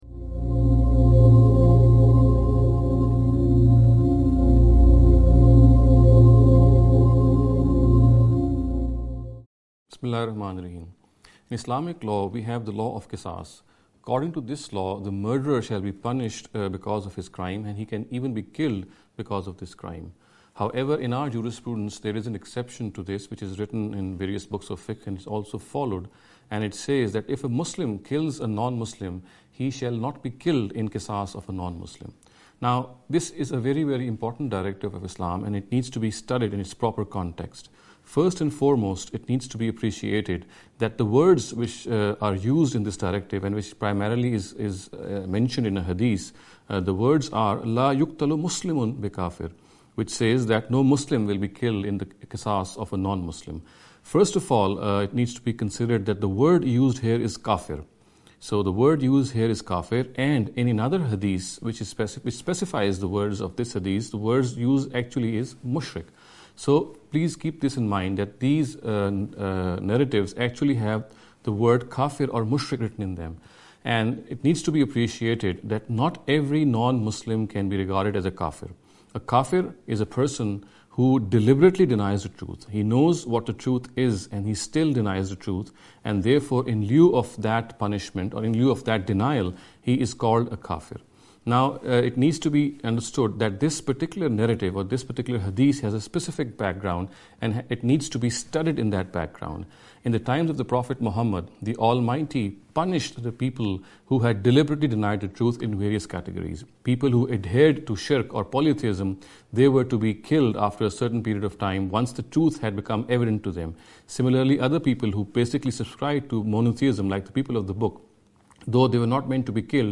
This lecture series will deal with some misconception regarding the Islam and Non-Muslims.